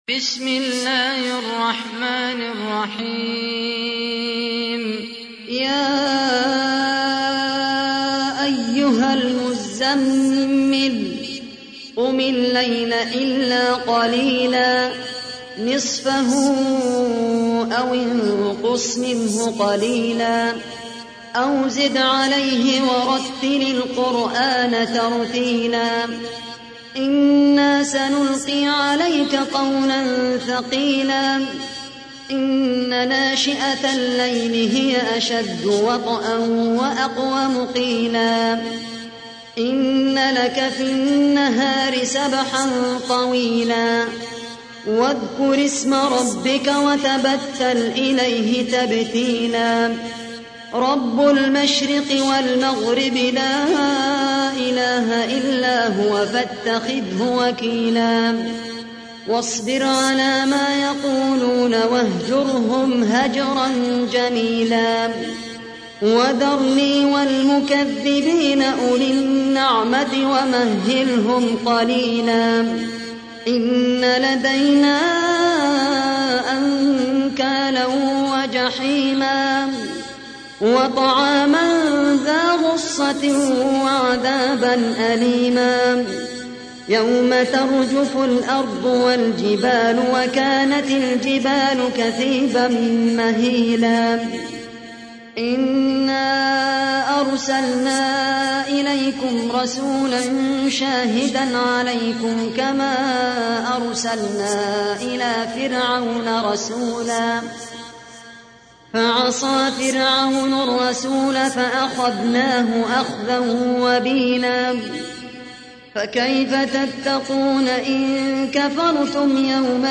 تحميل : 73. سورة المزمل / القارئ خالد القحطاني / القرآن الكريم / موقع يا حسين